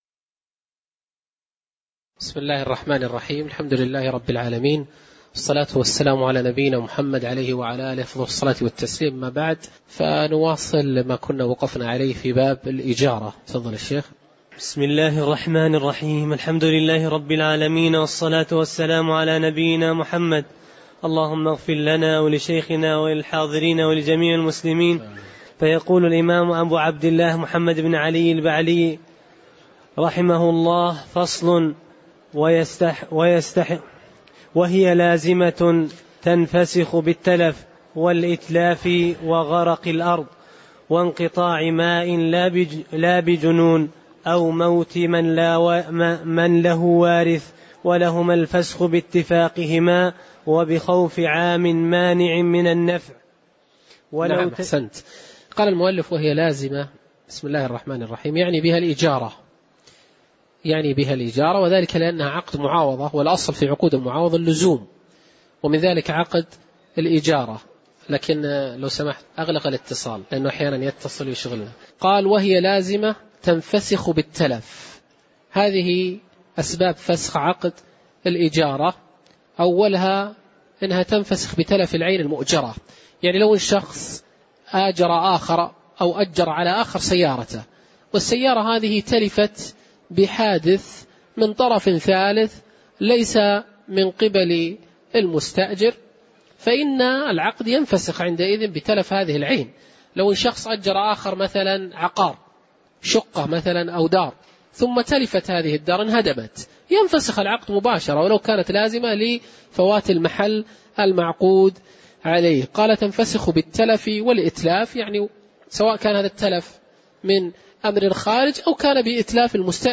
تاريخ النشر ١٩ شوال ١٤٣٩ هـ المكان: المسجد النبوي الشيخ